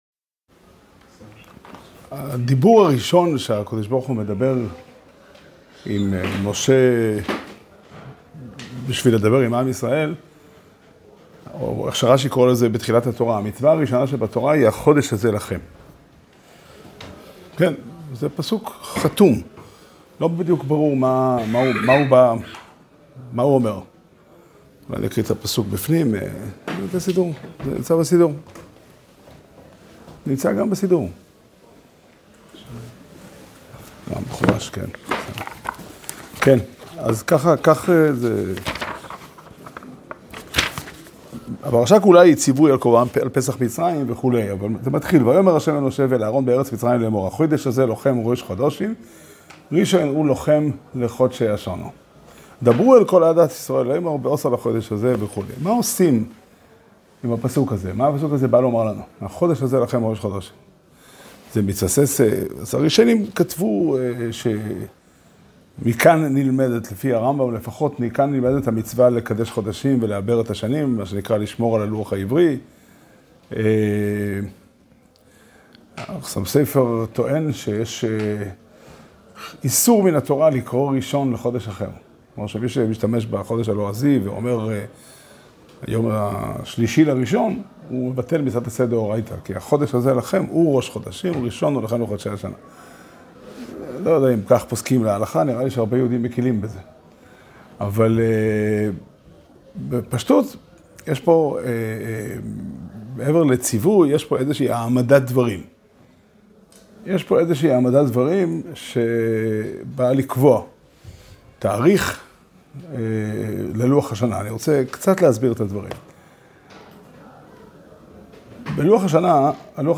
שיעור שנמסר בבית המדרש פתחי עולם בתאריך ט"ז אדר ב' תשפ"ד